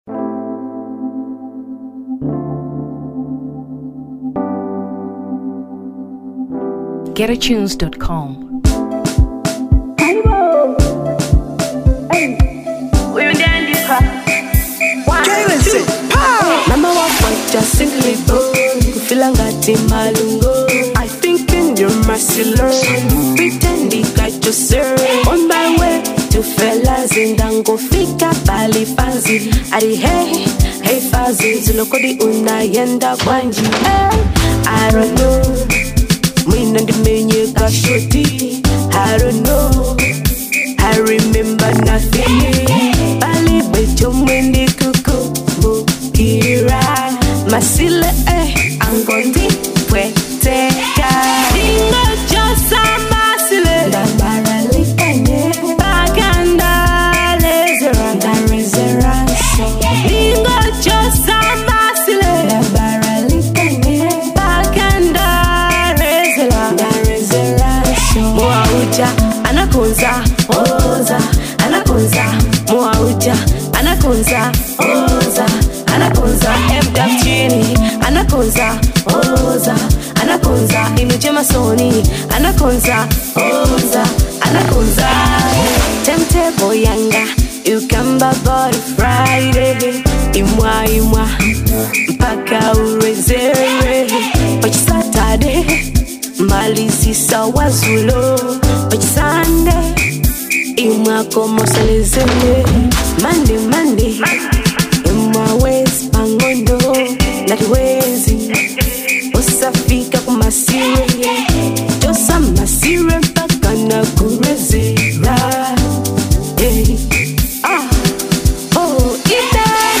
Amapiano 2023 Malawi